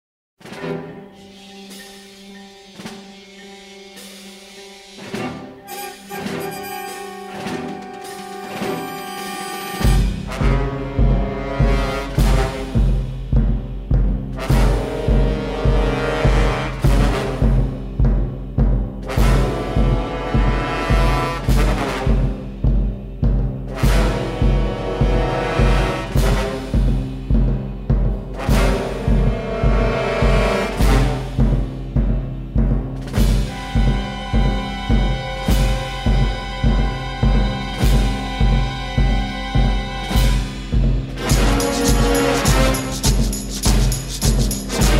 propulsive